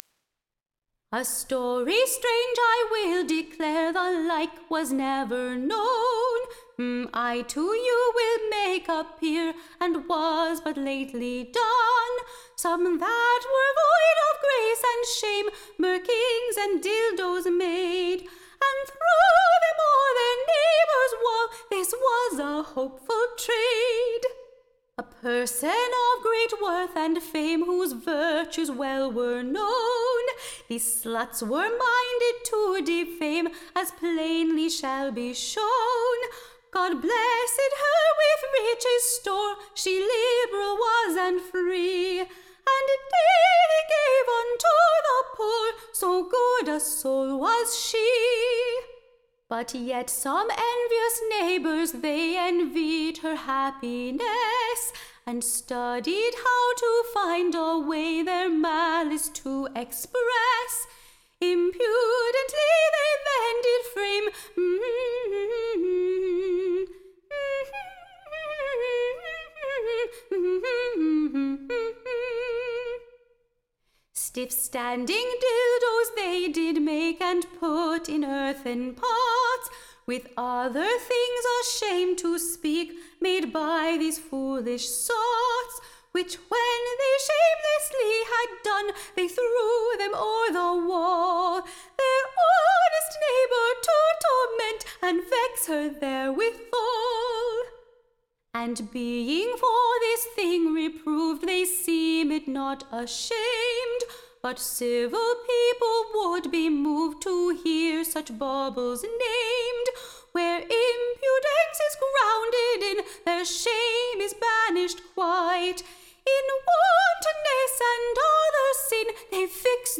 Recording Information Ballad Title News from CRUTCHET-FRYERS.